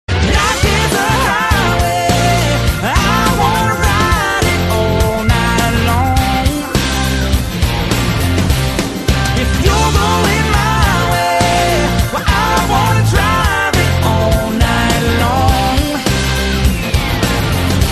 M4R铃声, MP3铃声, 欧美歌曲 35 首发日期：2018-05-15 09:39 星期二